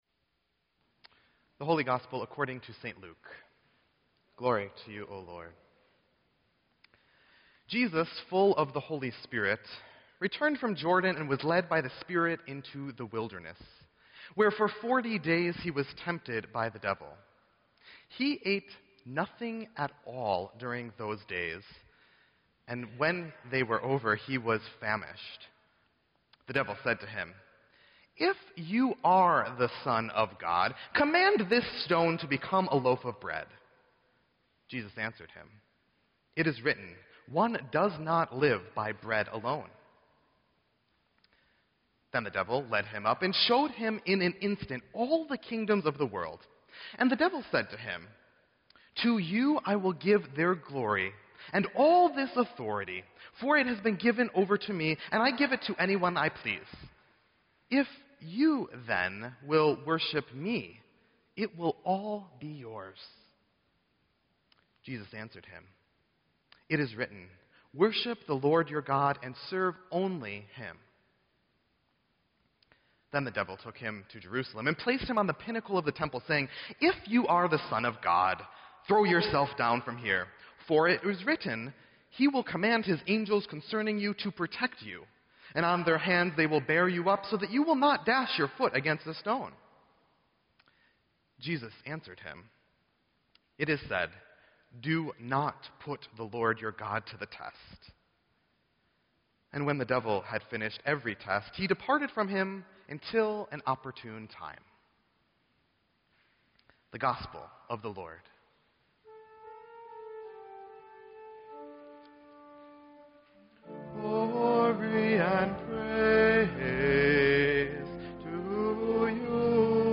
Sermon_2_15_16.mp3